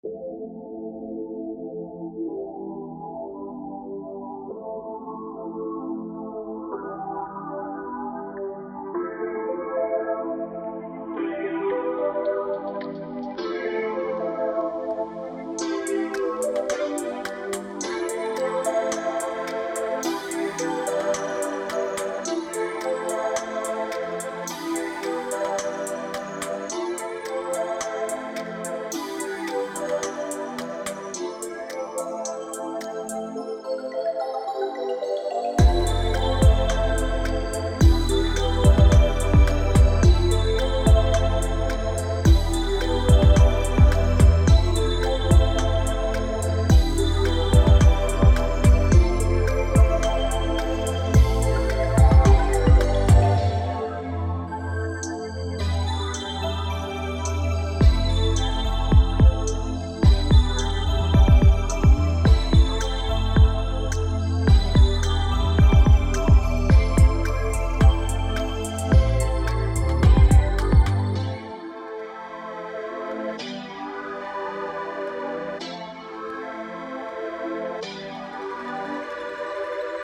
Here is a little snippet of the type of music I love to make, pretty much anything spacey or dreamscape.
Made in FL Studio.
dreamscape music lofi space
That phaser really hits a nice spot in my brain, lovely work!